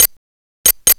TRIANGLELP-R.wav